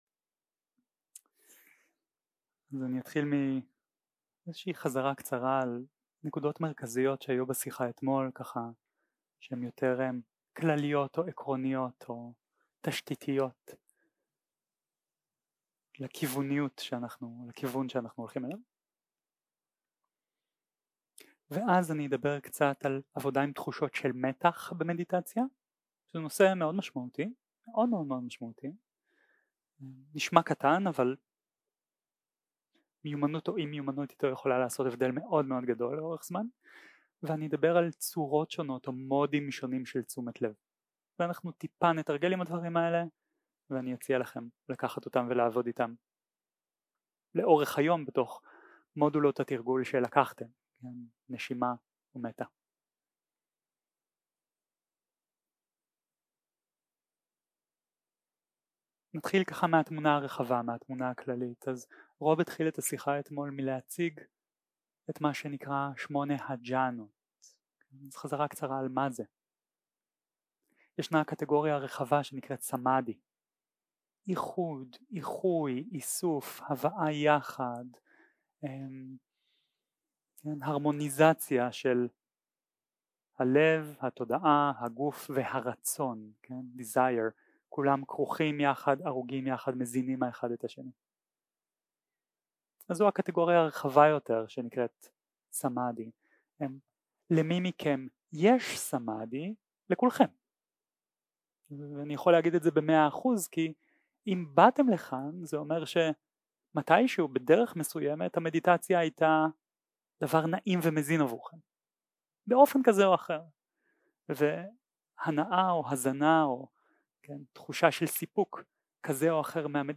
שיחת הנחיות למדיטציה שפת ההקלטה
ריטריט סמאדהי